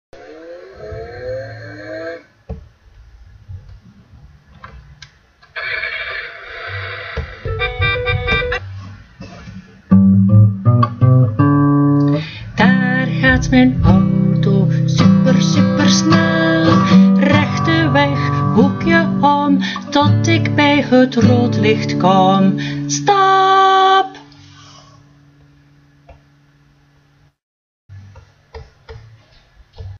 Het liedje is nog een kladversie : met wat meer tijd maak ik er wel een mooiere versie van.